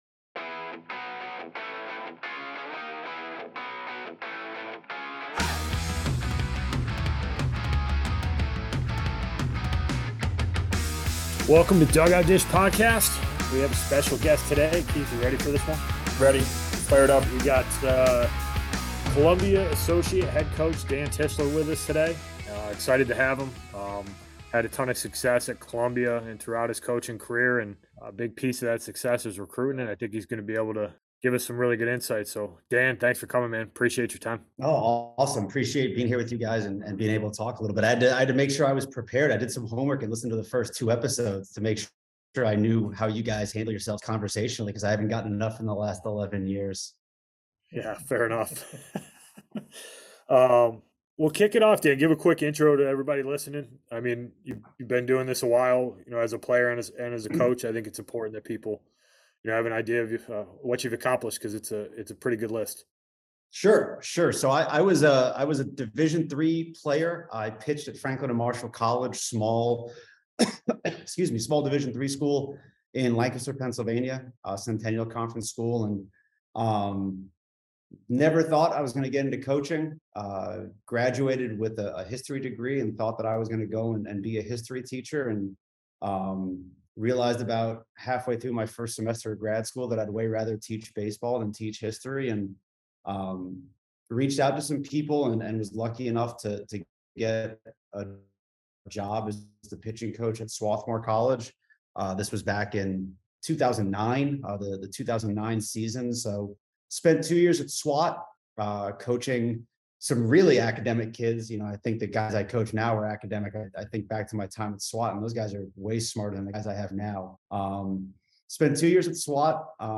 Episode 5: Interview